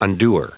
Prononciation du mot undoer en anglais (fichier audio)